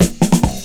DRUMFILL07-L.wav